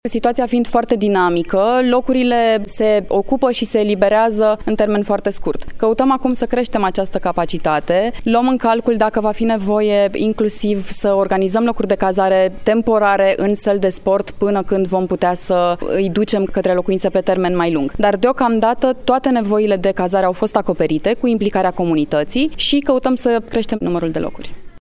Viceprimarul municipiului Brașov, Flavia Boghiu: